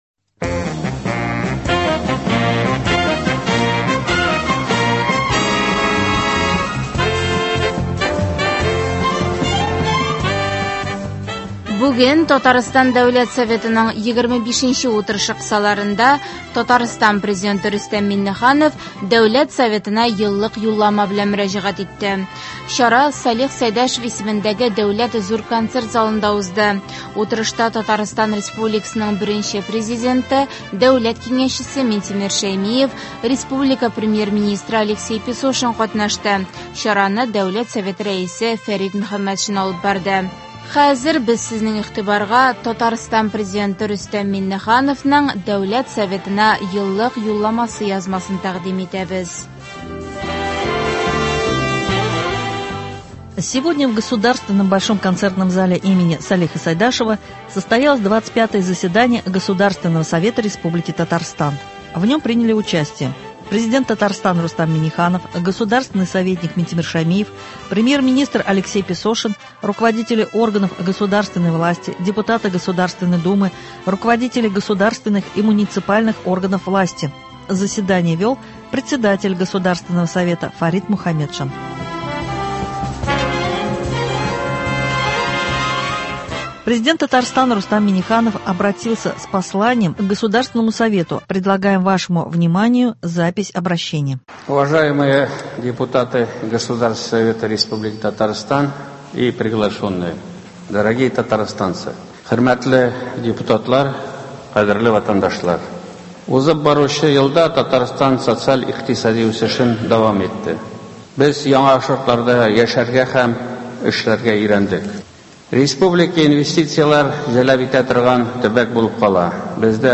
Чара Салих Сәйдәшев исемендәге Дәүләт Зур концерт залында узды.
Сегодня в Государственном Большом концертном зале имени Сайдашева состоялось 25 заседание Государственного совета республики Татарстан.
Заседание вел Председатель Государственного Совета Фарид Мухаметшин.Президент Татарстана Рустам Минниханов обратился с Посланием к Государственному совету.